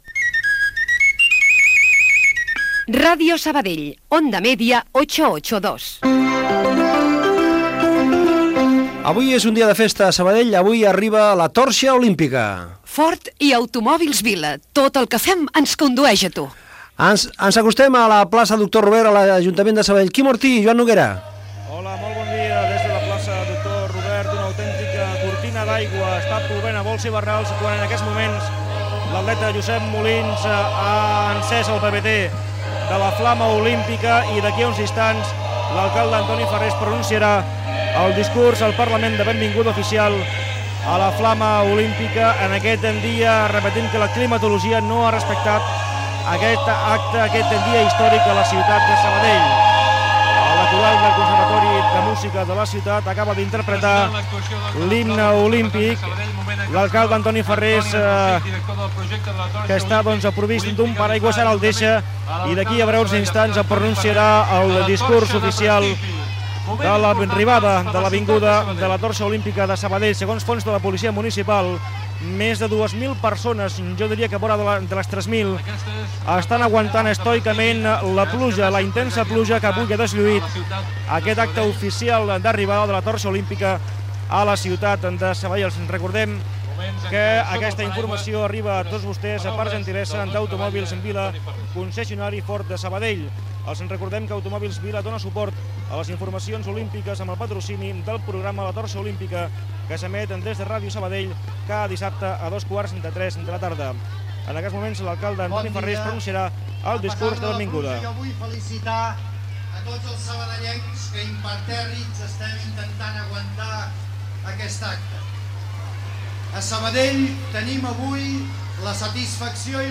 Indicatiu emissora. Arribada de la torxa olímpica a Sabadell. Informa des de la unitat mòbil
Paraules de l'alcalde de la ciutat Antoni Farrés
Entreteniment